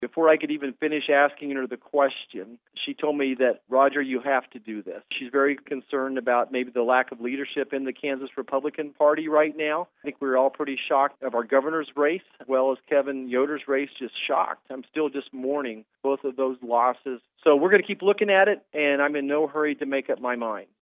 Marshall, speaking via conference call says he’s considering a Senate run, but stopped short of committing to it, noting he has the full support of his wife.